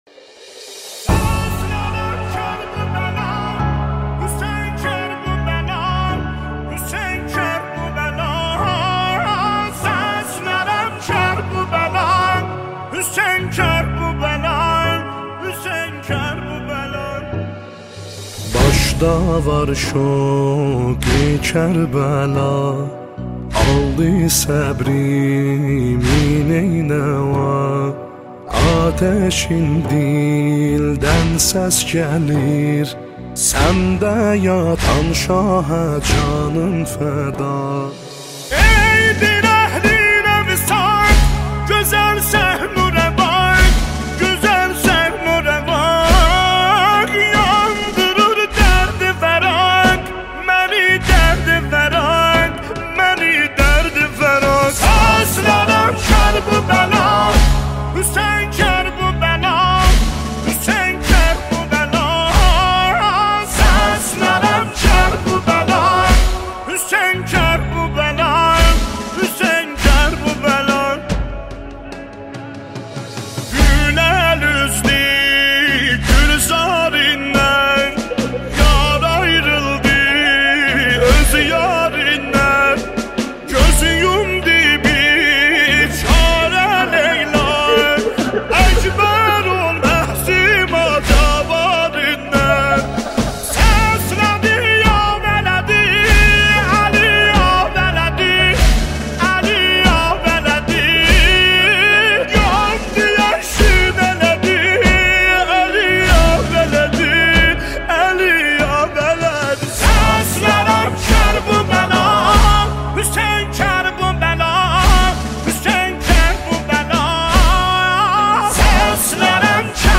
نماهنگ آذری
مداح آذری